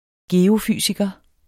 Udtale [ ˈgeːo- ]